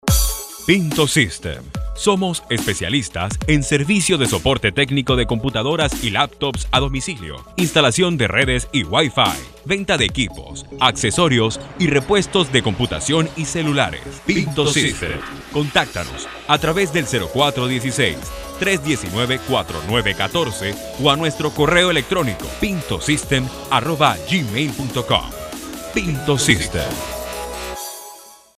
I have Spanish Neutral accent. I have my own studio with professional equipments, for record my proyects.
Sprechprobe: Werbung (Muttersprache):
PINTO SYSTEM-COMMERCIAL_3.mp3